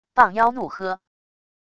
蚌妖怒喝wav音频